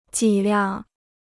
脊梁 (jǐ liang) Dictionnaire chinois gratuit